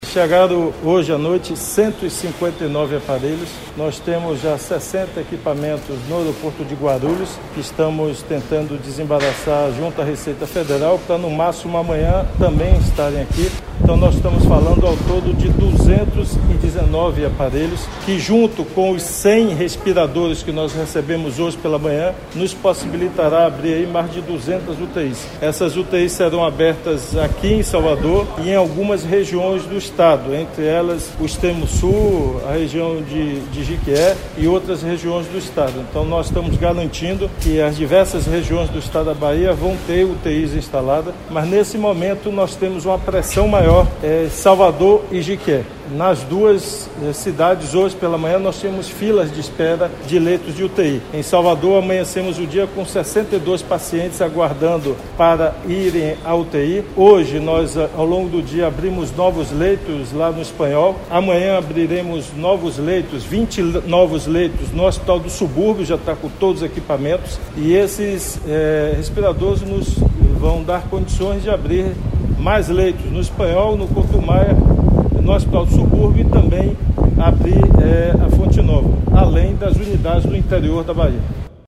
“Com esses respiradores, nós vamos montar leitos de UTI. São respiradores que, a partir de amanhã (21), começam a ser distribuídos nas nossas unidades hospitalares em Salvador e no interior do estado, em várias regiões”, afirmou Rui durante live nas redes sociais direto do aeroporto, destacando a preocupação com Jequié, que nesta quarta chegou a 201 casos confirmados da Covid-19.